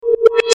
Boop Two